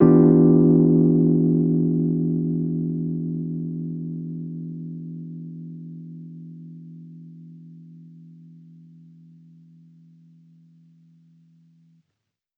Index of /musicradar/jazz-keys-samples/Chord Hits/Electric Piano 1
JK_ElPiano1_Chord-Em7b9.wav